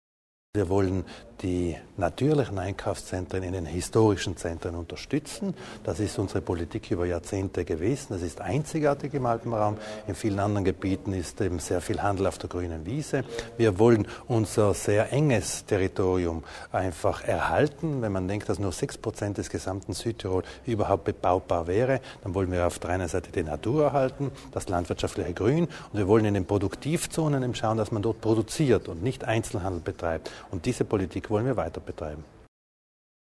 Öffnungszeiten der Geschäfte: Landesrat Widmann erklärt den Unterschied zwischen Zentrum und aussserstädtische Zonen